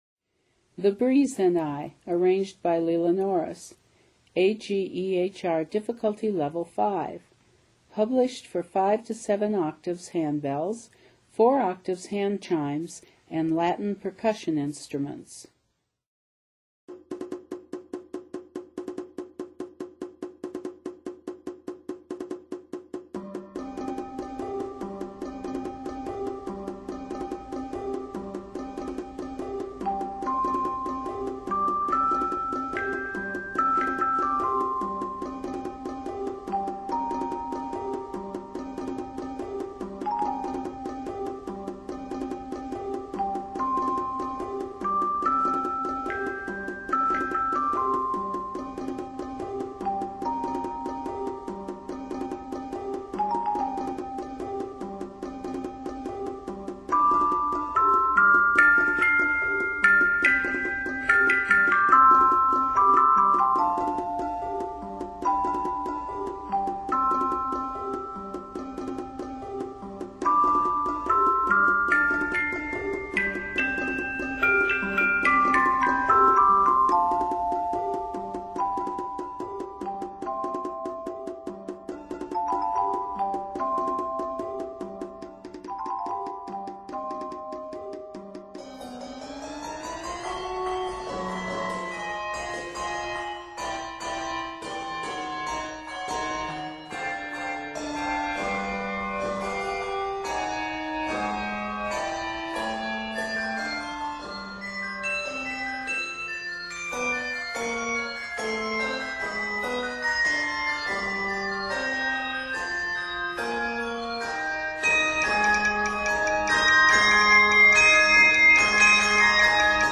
is arranged in C Major, Ab Major, and A Major